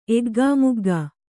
♪ eggāmuggā